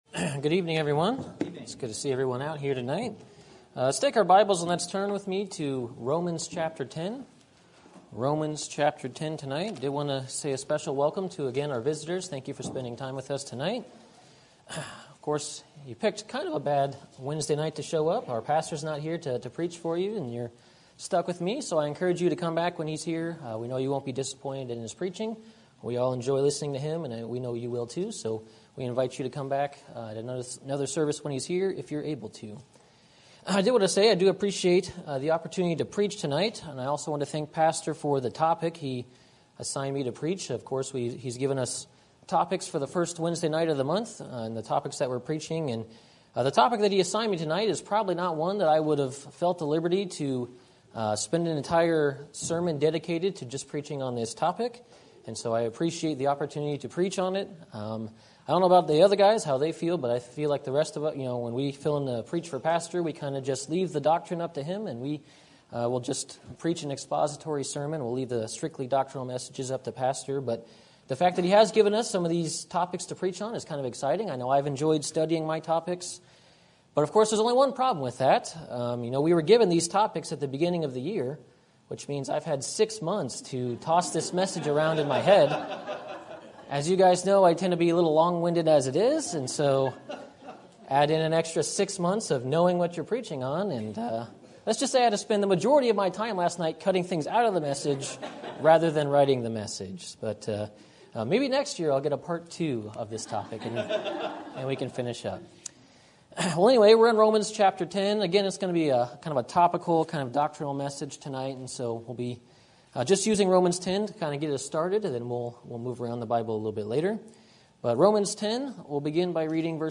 Sermon Topic: General Sermon Type: Service Sermon Audio: Sermon download: Download (22.4 MB) Sermon Tags: Romans Calvinism Doctrine Dangers